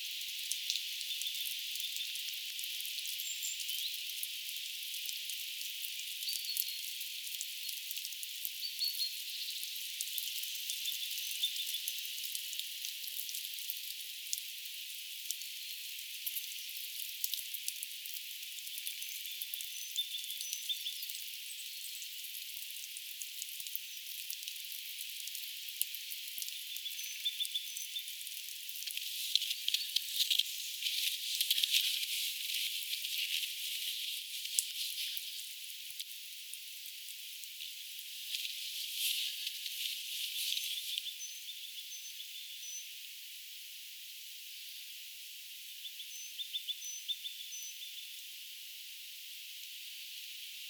erikoista ehkä pähkinänakkelin laulun inspiroimaa
talitiaisen laulua
erikoista_talitiaisen_laulua_ehka_voi_sanoa_etta_pahkinanakkelin_inspiroimaa.mp3